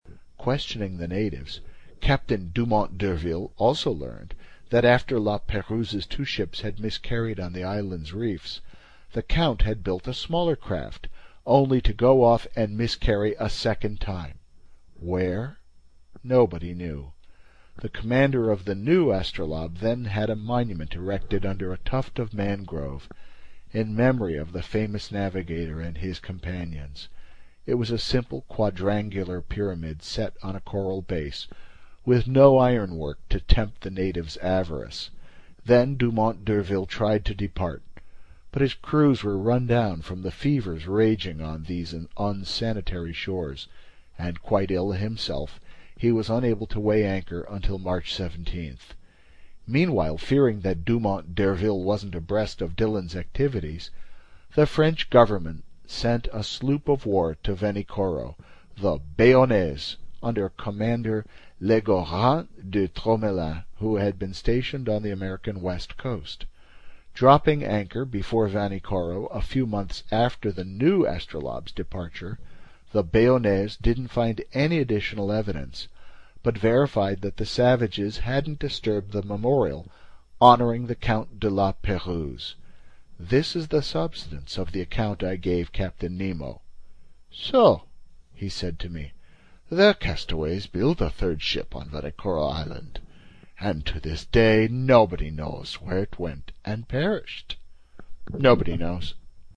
英语听书《海底两万里》第251期 第19章 万尼科罗群岛(13) 听力文件下载—在线英语听力室
在线英语听力室英语听书《海底两万里》第251期 第19章 万尼科罗群岛(13)的听力文件下载,《海底两万里》中英双语有声读物附MP3下载